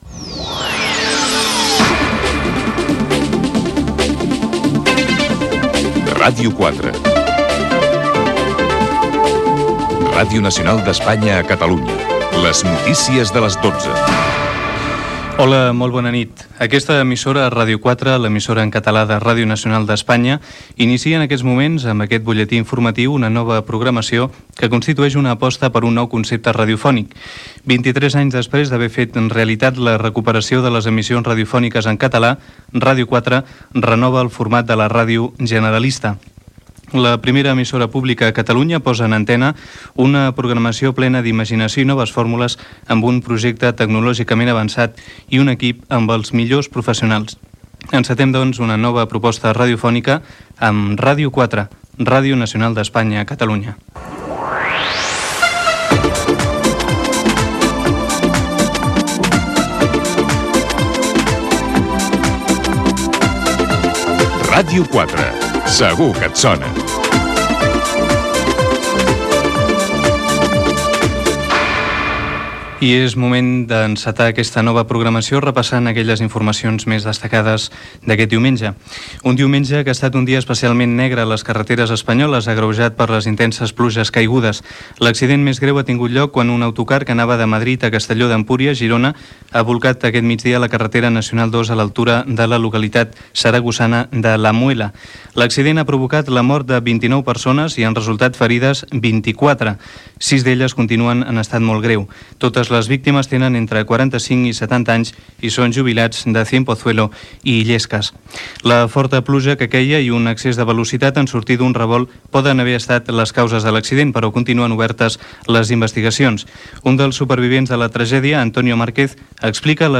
Careta d'entrada, avís de la renovació de la programació de Ràdio 4, indicatiu de l'emissora, accidents d'un autocar a La Muela i Lorca, avaria de Fecsa al Vallès Oriental, sondejos de la intenció de vot a les eleccions a la Generalitat, resultats de la lliga de futbol, careta de sortida i indicatiu de l'emissora.
Informatiu